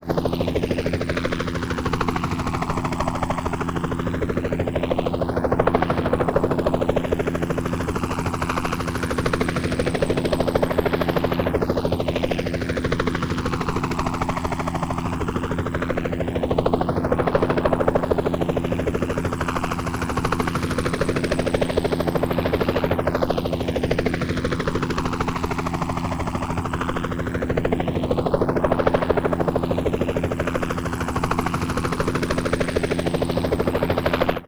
helicopter-headphones.aiff